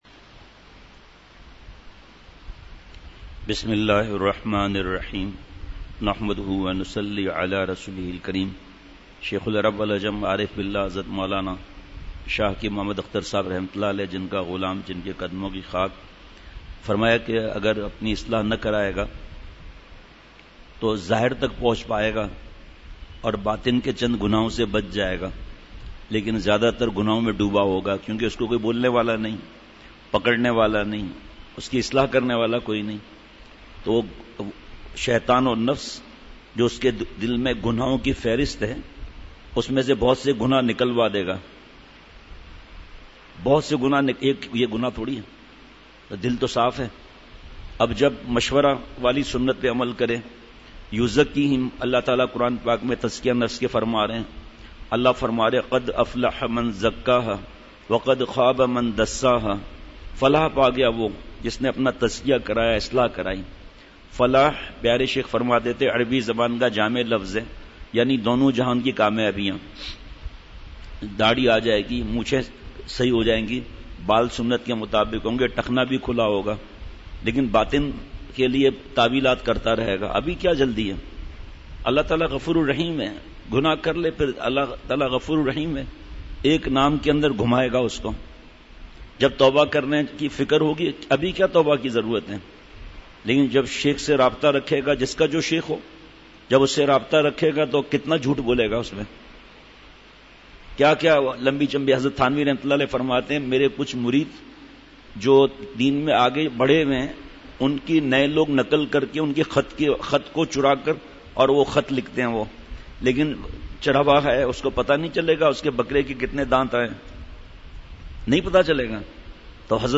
مجلس ۶ جولائی ۲۰۱۹ء بعد عصر : عارضی حسن سے دھوکہ مت کھائیے !